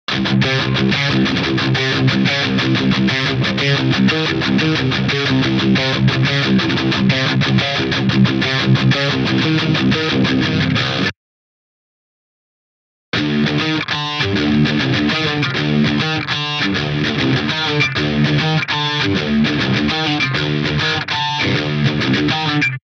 Учусь играть тяж-риффы.
Три варианта настройки BIAS-FX. Два риффа в одном файле. Во втором другой медиатор (толстый 2 мм, кажется у него атака похуже?)
Датчики активные Duncan так сказать Designed.